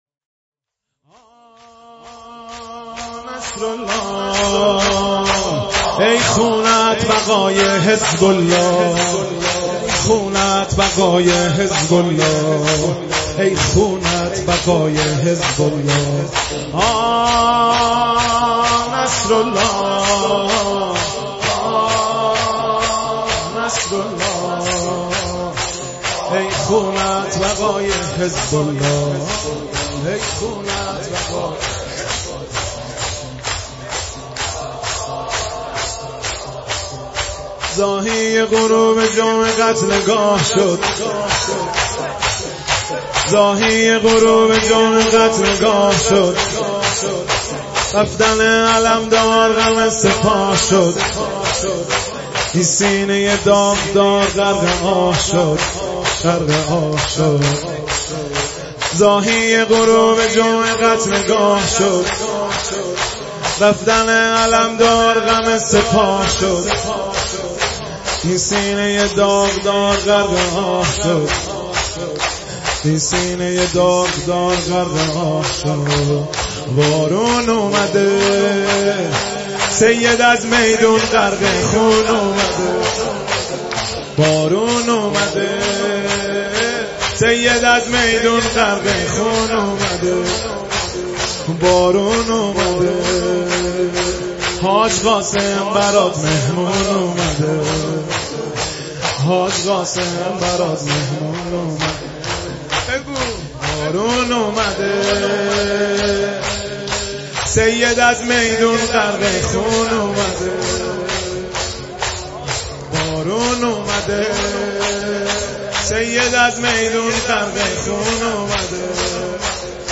با نوای دلنشین
مداحی حماسی مداحی مقاومت